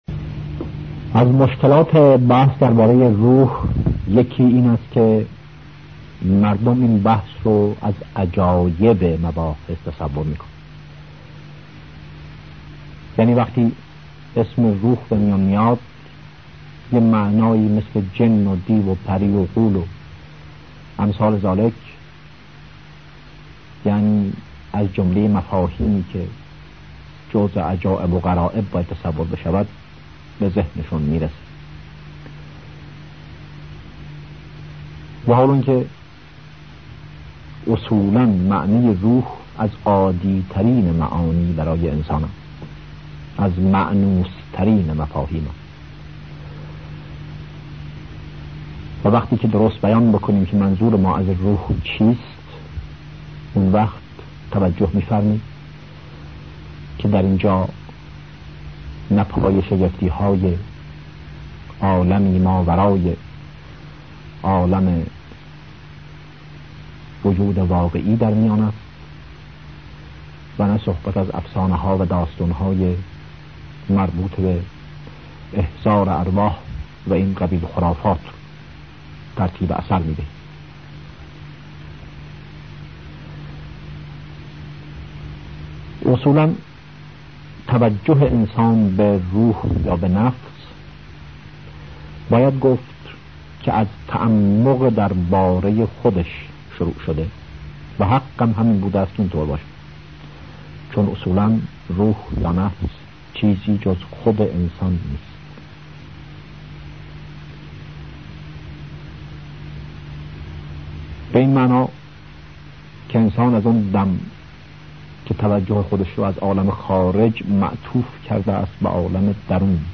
Download 036 BAGHAYE ROOH.MP3 سایر دسته بندیها سخنرانی هایی پیرامون عقاید بهائی 19478 reads Add new comment Your name Subject دیدگاه * More information about text formats What code is in the image?